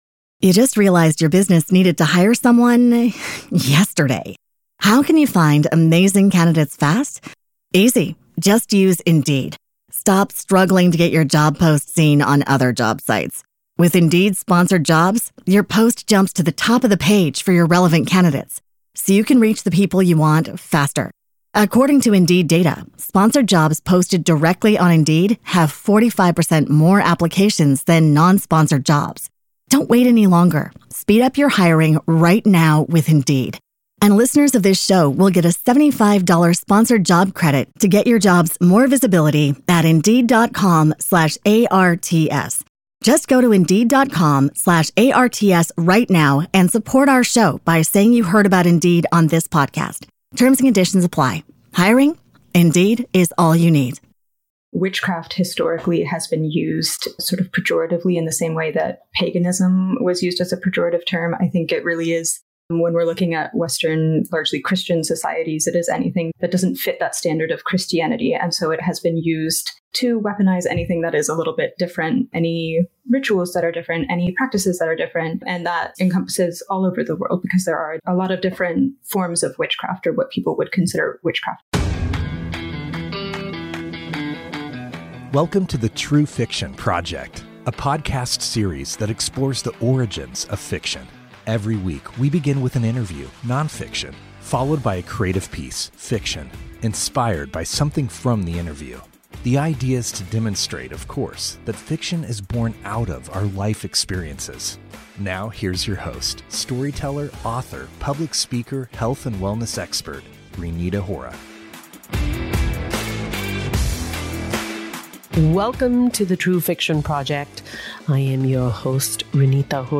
At the end of the episode, you’ll hear an excerpt of her novel, which is when the main character, Rose, is visited by a ghostly vision in the bath.